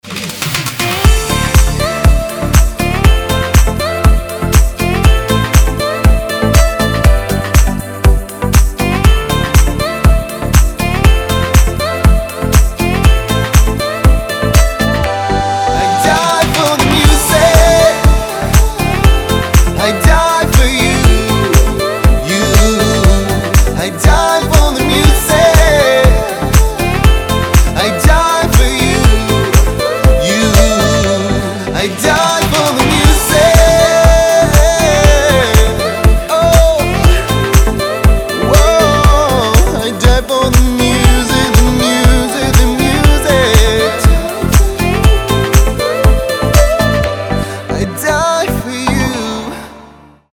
позитивные
мужской вокал
красивые
dance
house